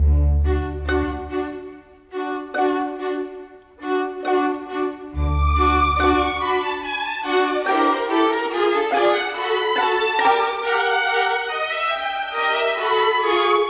Modern 26 Strings 01.wav